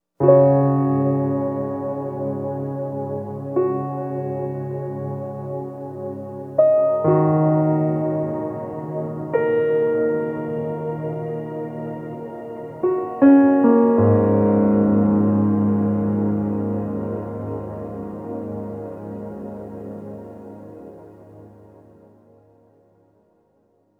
Reverb Piano 09.wav